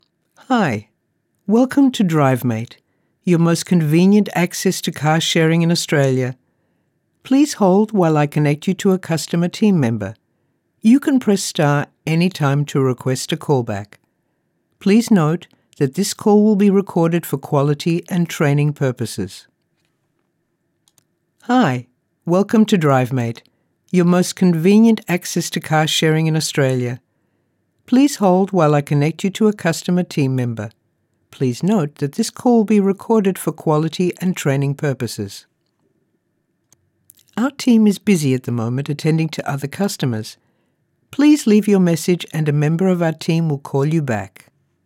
Female
English (Australian)
My voice overs are confident, warm, conversational, expressive, engaging, versatile and clear.
Phone Greetings / On Hold
IVR Demo for Drivemate company.